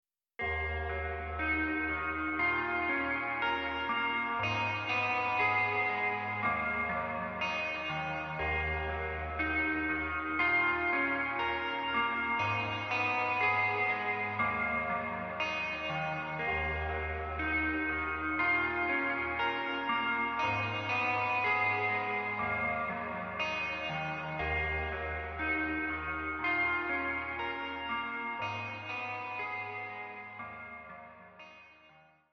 Waveformのパターンジェネレータを生かして出来たデモ曲。